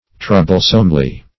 [1913 Webster] -- Trou"ble*some*ly, adv.